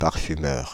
Ääntäminen
Ääntäminen France (Île-de-France): IPA: /paʁ.fy.mœʁ/ Paris: IPA: [paʁ.fy.mœʁ] Haettu sana löytyi näillä lähdekielillä: ranska Käännöksiä ei löytynyt valitulle kohdekielelle.